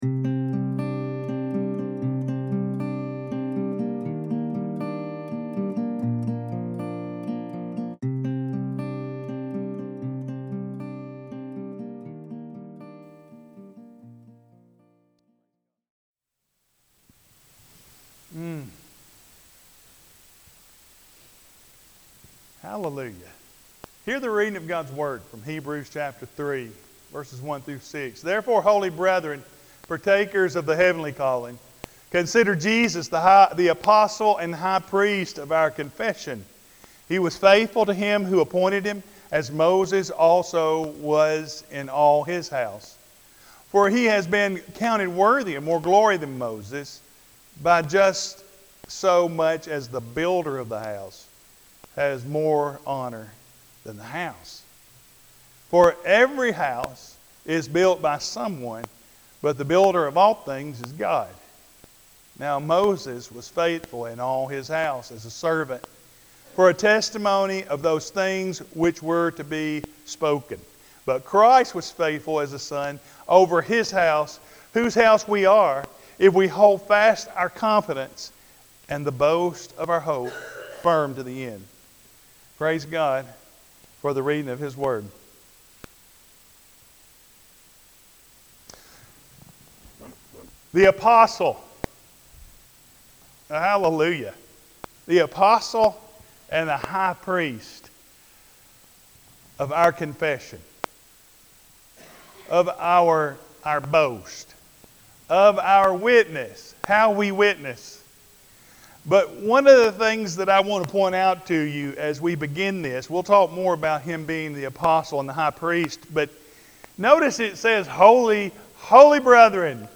Apr 26, 2026 Jesus, The Apostle And Our High Priest – April 26th, 2026 MP3 SUBSCRIBE on iTunes(Podcast) Notes Discussion Today’s sermon calls us to fix our thoughts on Christ Jesus, the faithful Son over God’s house, greater than Moses who served within it. If we belong to His house, we will hold firmly to our confidence and hope in Him, living as the new creation He has made us to be.